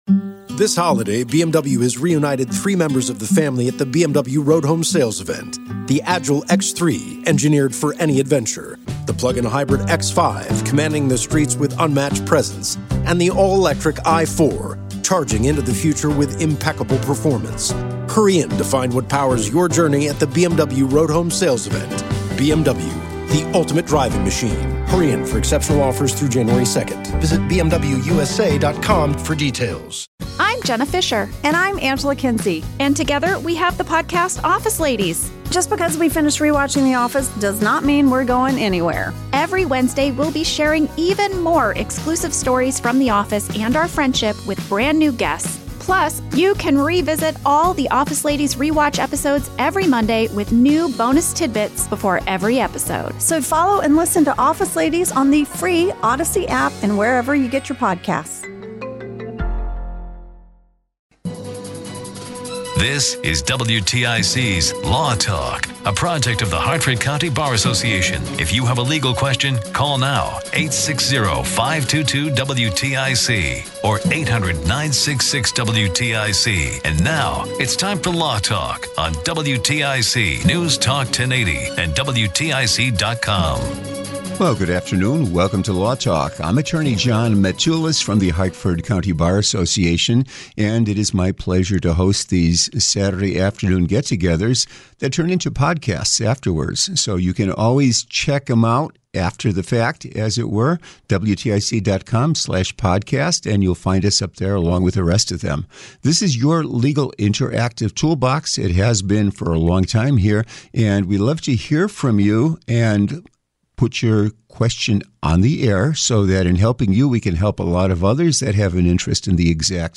Listener calls